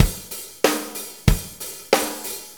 Shuffle Loop 28-10.wav